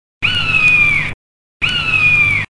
Buse (bird) Sound Effect
buse-bird.mp3